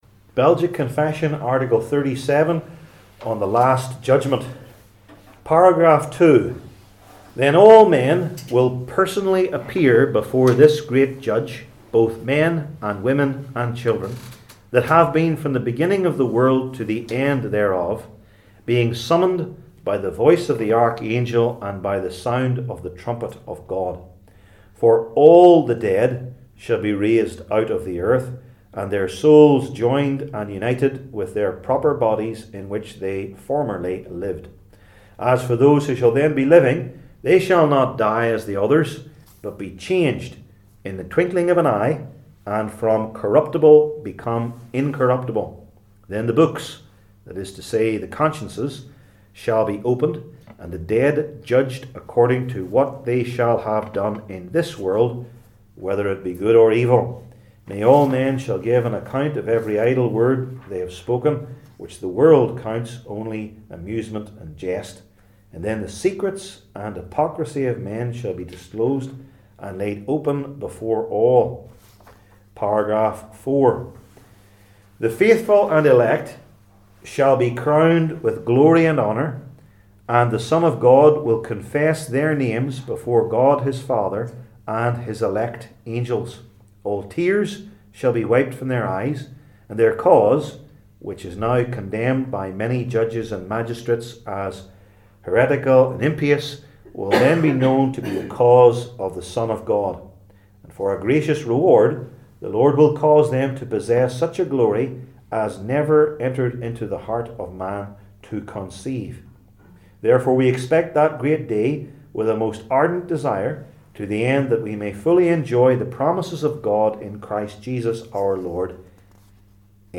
Series: Belgic Confession 37 , The Last Judgment Passage: II Timothy 3 Service Type: Belgic Confession Classes